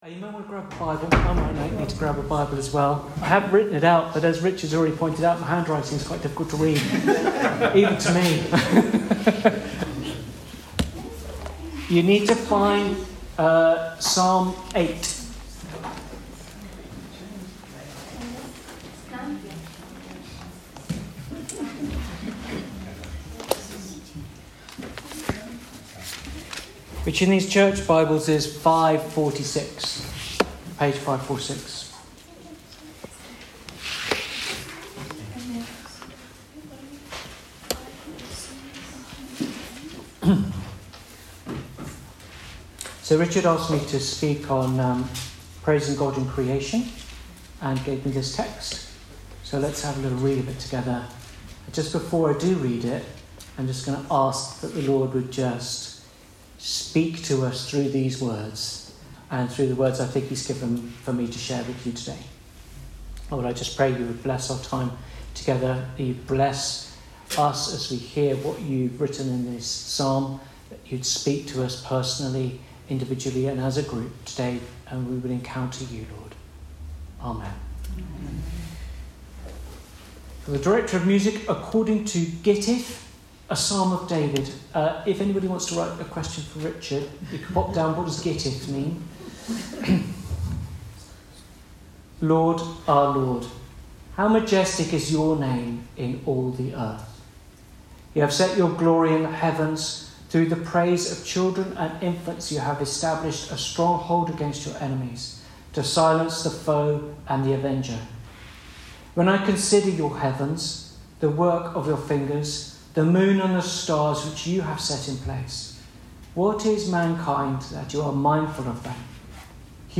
Weekly message from The King’s Church.